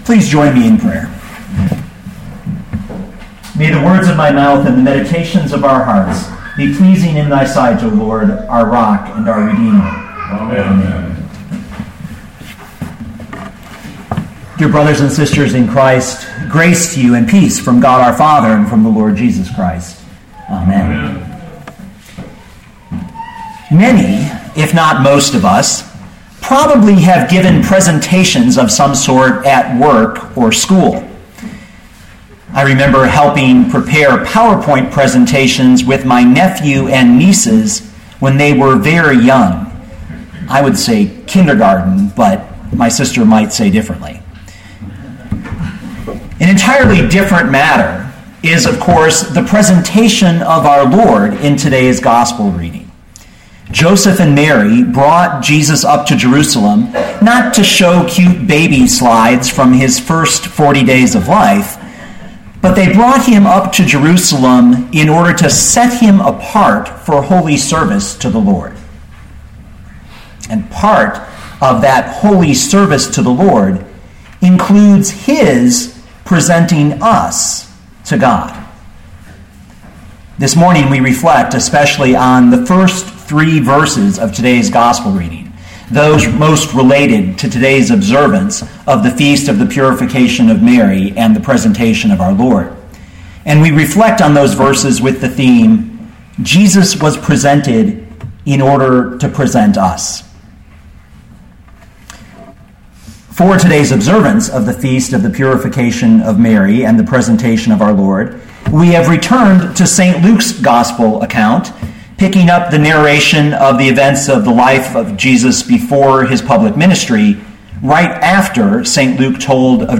2014 Luke 2:22-24 Listen to the sermon with the player below, or, download the audio.